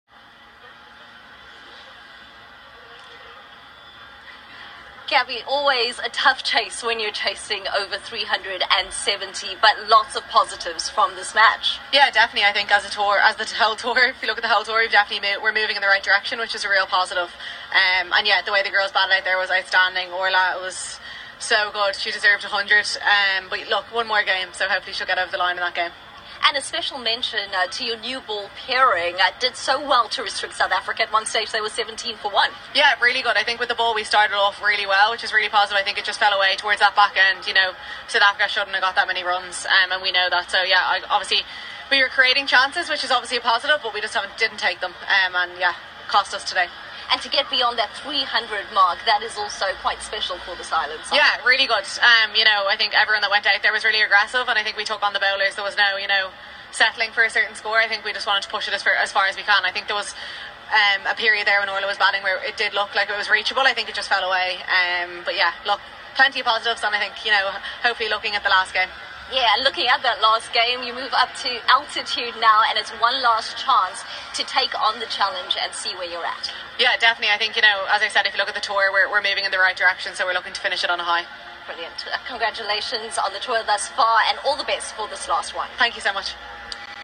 Gaby Lewis post-match interview